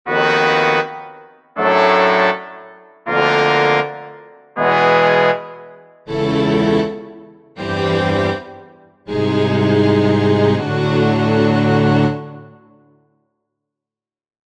The fundamental harmonies are retained. The solemn instrumental color is held fast.
The harmonies are still triads, but tonality, with its benison of restfulness, has been sacrificed. The phrase is in no key, or rather it is in as many keys as there are chords.